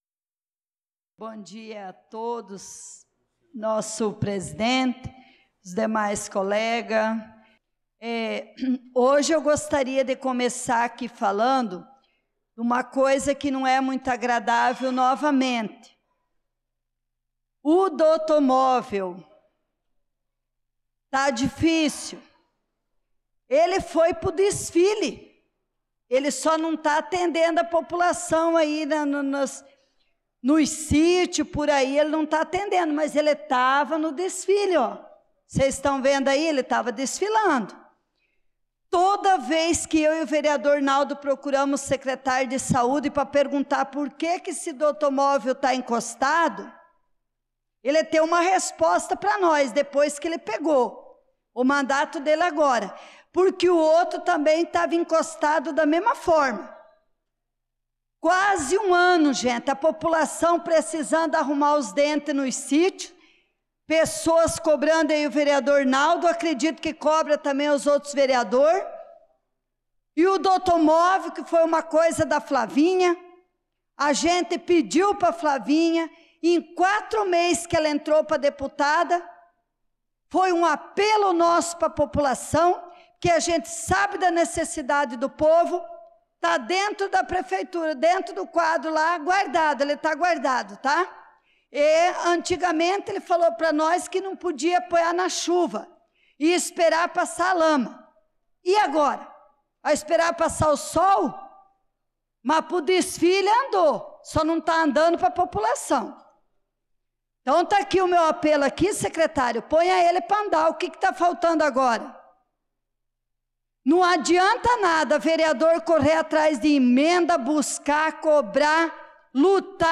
Pronunciamento da vereadora Leonice Klaus na Sessão Ordinária do dia 26/05/2025